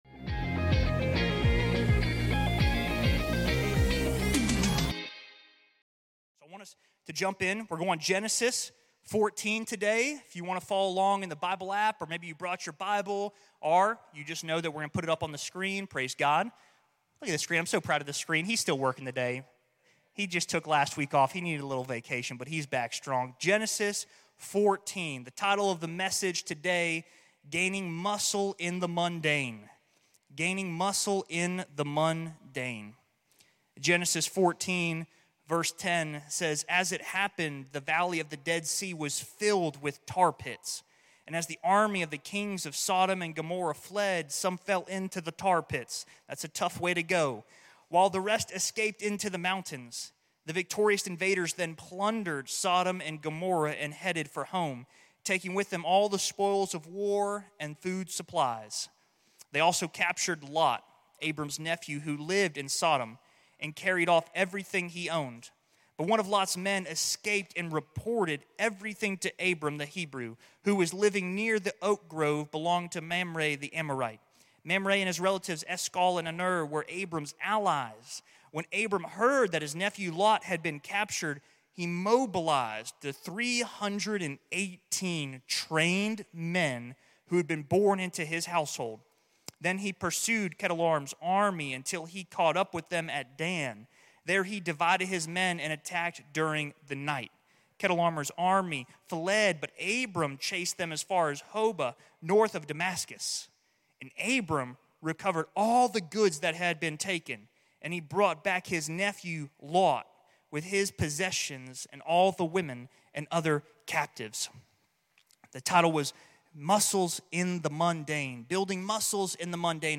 How do we train ourselves so that we are armed when the enemy attacks? 1) Read the Bible consistently 2) Pray often, giving God room to speak to you 3) Worship God every day of the week 4) Live in community with fellow believersThe Vertical Summit was also held at the end of the service, so check that out!